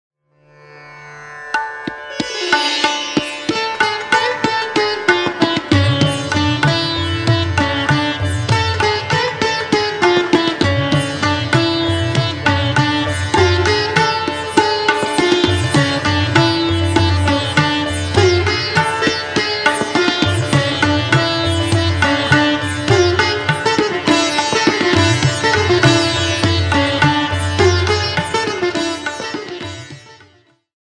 an accoustic East West Blend
Sitar
Guitar